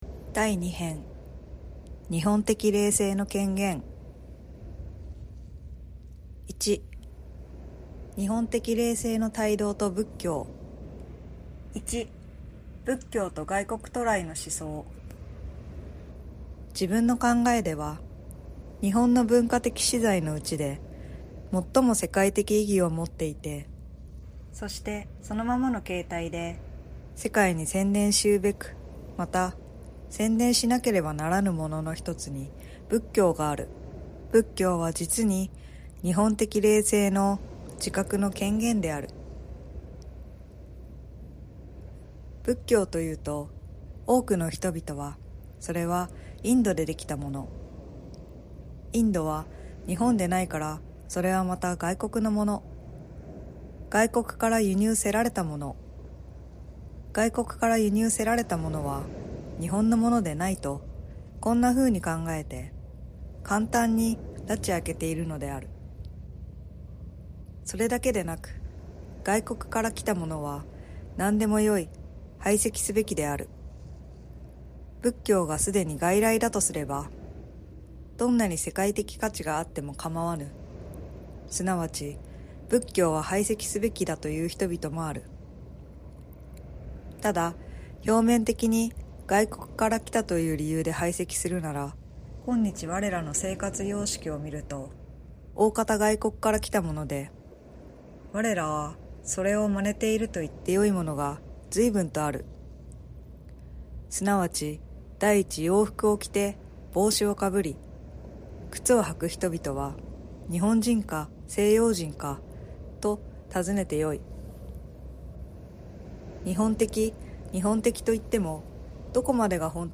『日本的霊性』③第二篇 日本的霊性の顕現 １日本的霊性の胎動と仏教 作：鈴木大拙 朗読
心を豊かにする朗読。